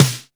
SC SNARE 2.wav